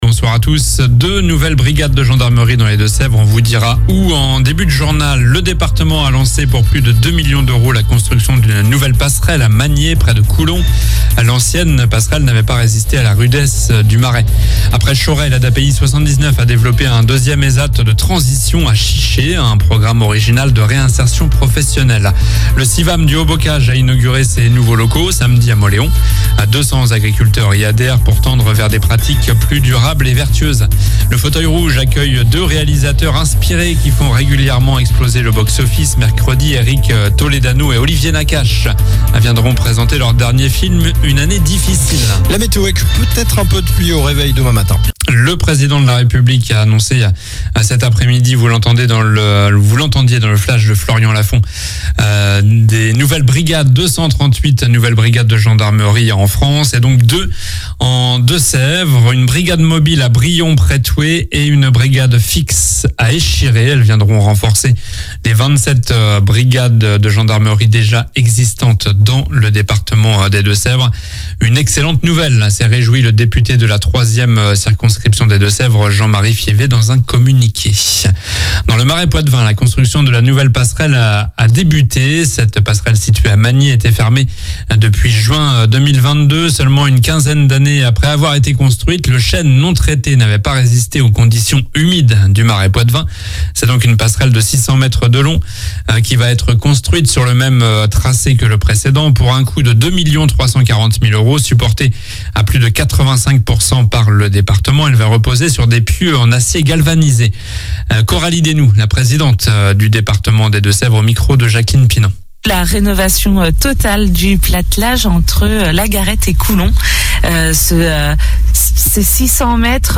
Journal du mardi 2 octobre (soir)